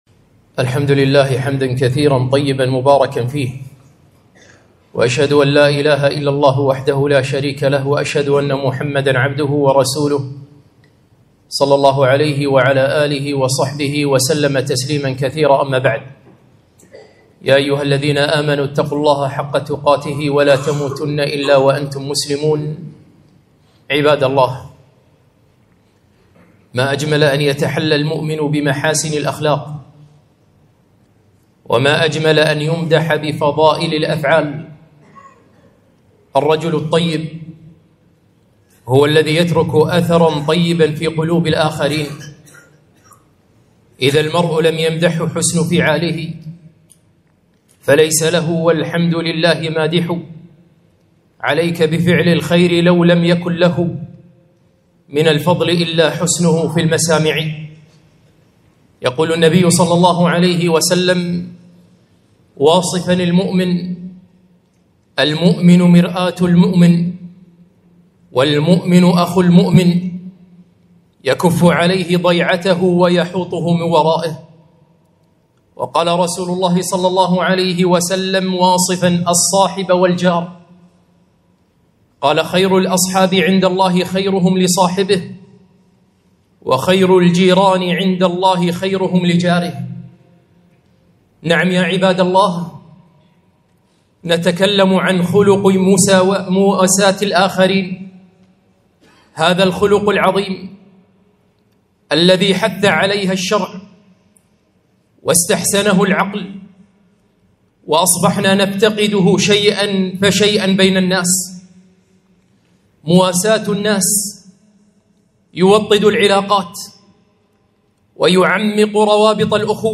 خطبة - مواساة الناس